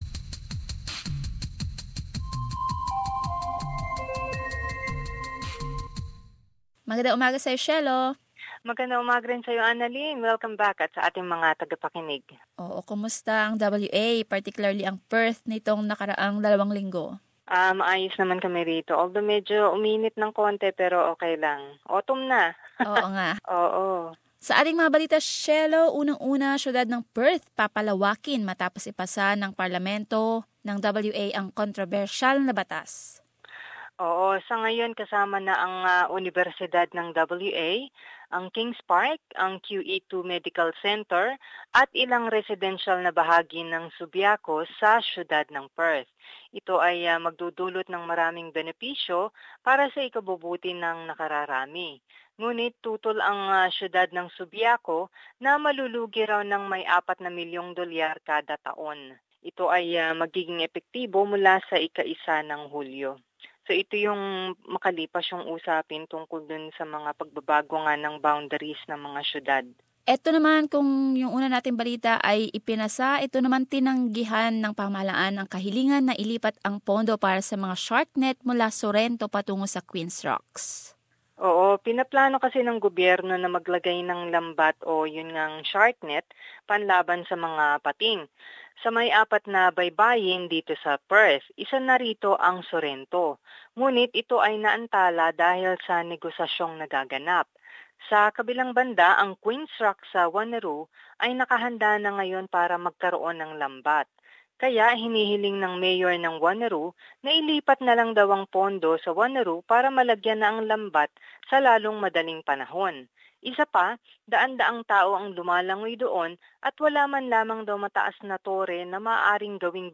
Perth Report.